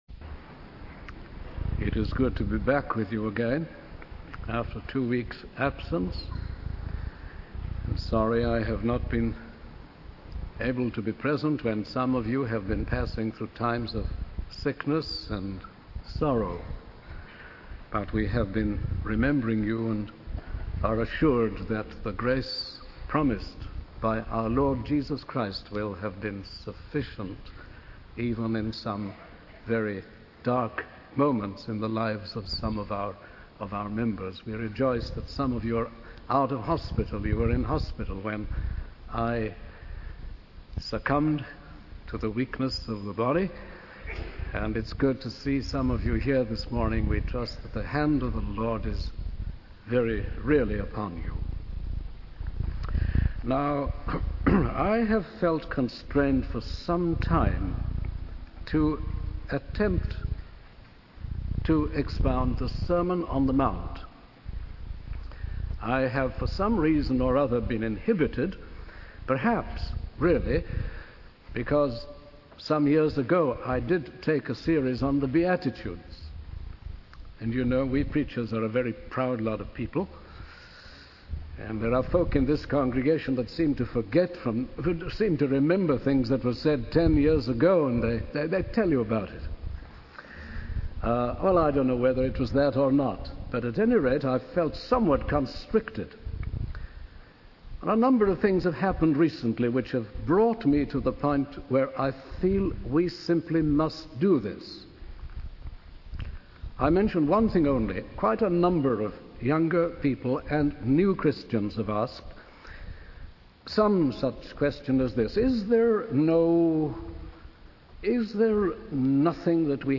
The speaker encourages the congregation to memorize the Sermon on the Mount as a source of guidance and ammunition when facing moral and ethical challenges in the world.